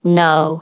synthetic-wakewords
ovos-tts-plugin-deepponies_GLaDOS_en.wav